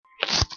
Sound Effects
Weird Walking Noise